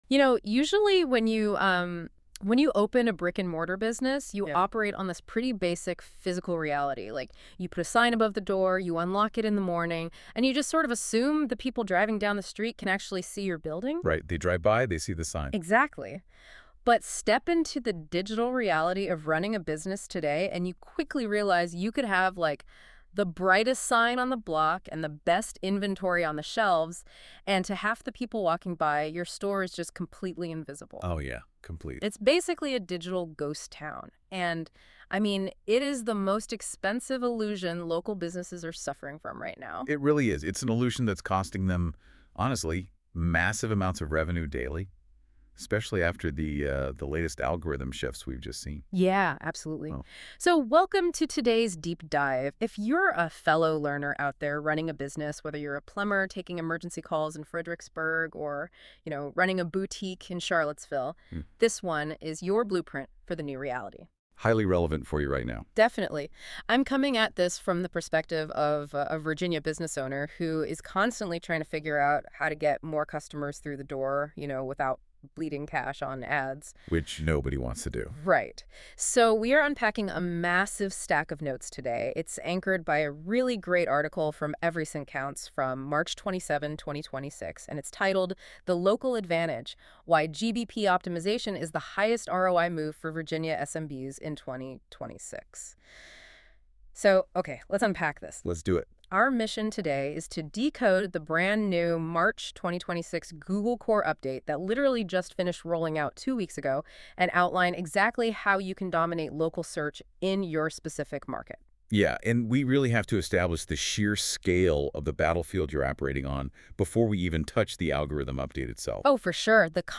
Go Deeper: Listen to the Full Podcast Discussion
Audio generated via Google NotebookLM — sourced from the research and references underlying this post.